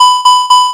New warning sounds